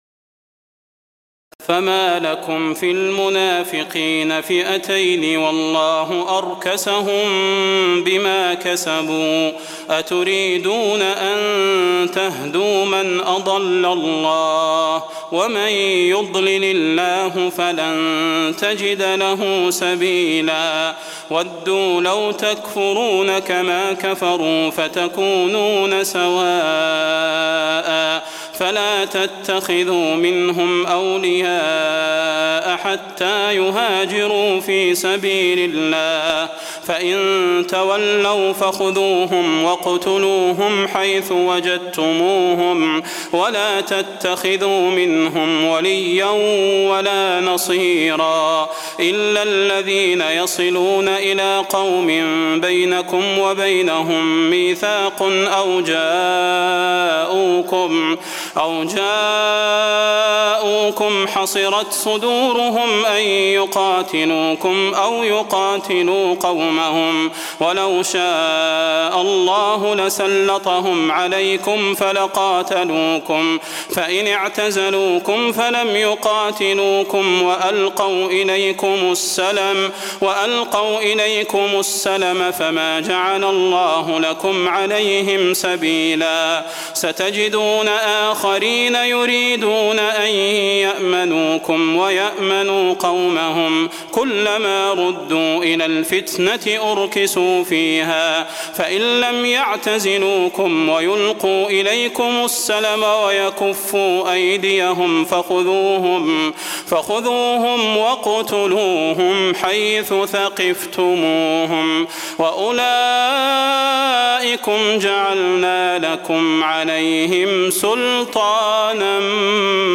تراويح الليلة الخامسة رمضان 1423هـ من سورة النساء (88-134) Taraweeh 5 st night Ramadan 1423H from Surah An-Nisaa > تراويح الحرم النبوي عام 1423 🕌 > التراويح - تلاوات الحرمين